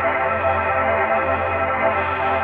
shadowloop.wav